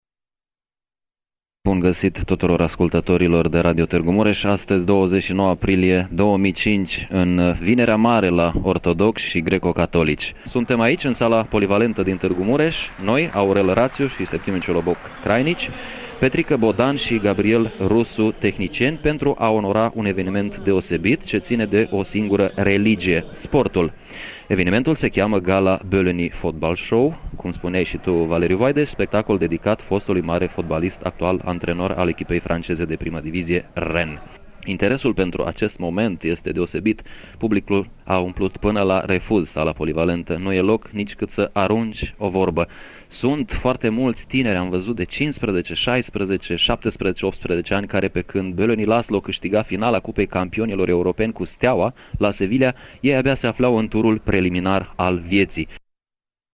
La Gala Bölöni am fost prezenți și noi, am transmis pe unde radio un spectacol de excepție, cu oameni importanți din lumea sportului și cu un public pe măsură.
Cei care nu au fost în Sala Sporturilor au aflat de la radio cum a fost primit și celebrat Laci Bölöni, marea glorie a ASA: